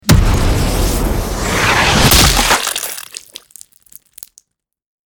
Download Gun sound effect for free.
Gun